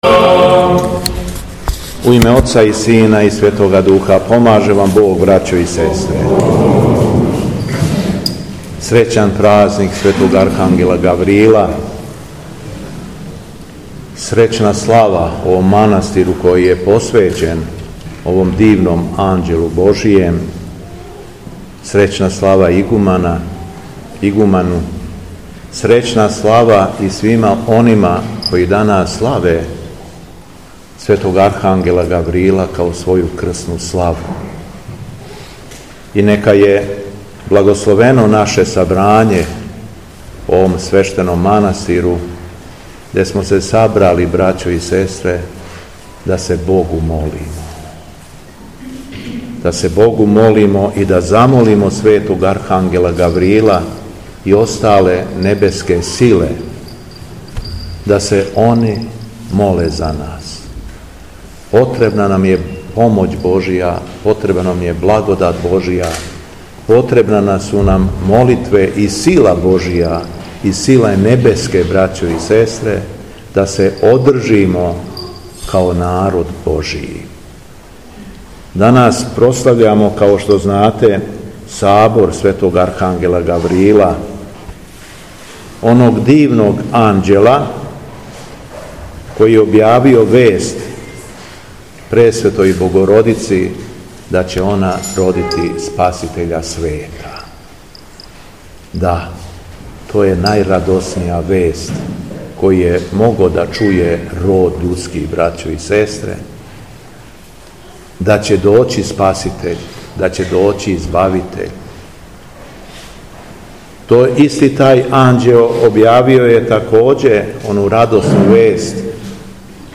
Беседа Његовог Високопреосвештенства Митрополита шумадијског г. Јована
Након прочитаног зачала из Светог Јеванђеља Високопреосвећени се обратио окупљеном народу беседом: